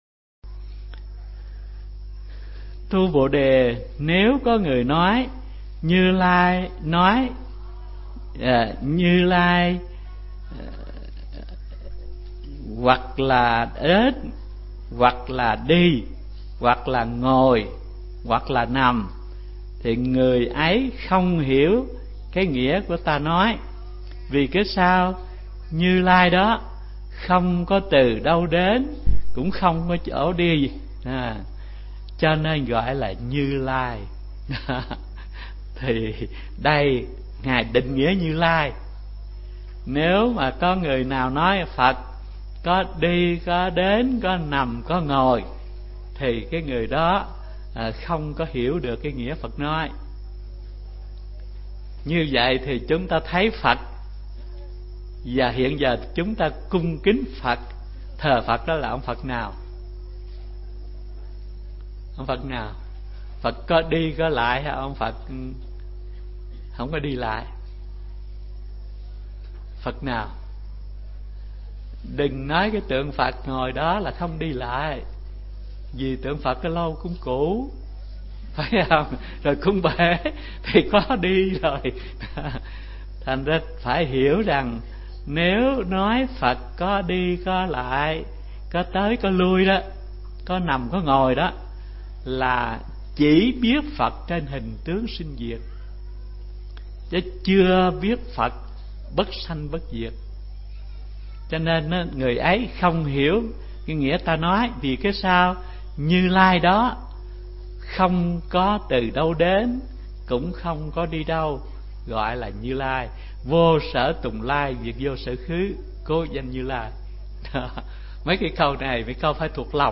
Nghe mp3 Pháp Âm Giảng Kinh Kim Cang 11 – Hòa Thượng Thích Thanh Từ